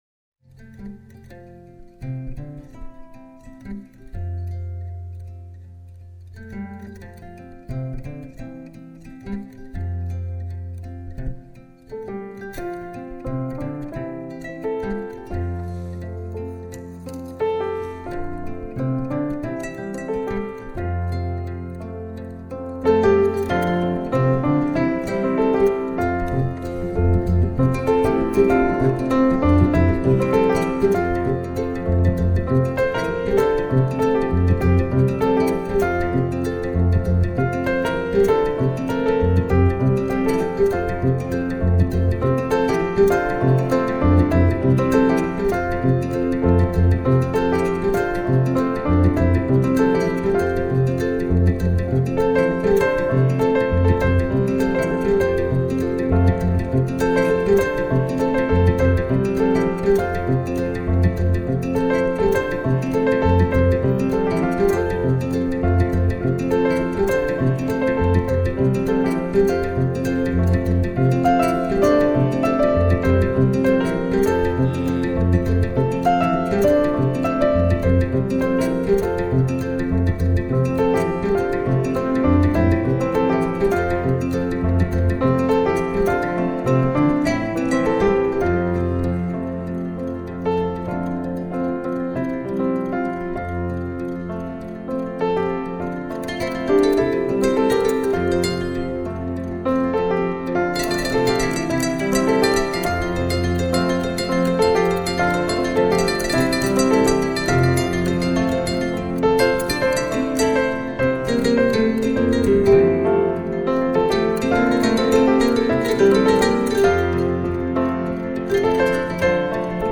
Neo-Classical, World Music